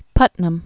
(pŭtnəm), Israel 1718-1790.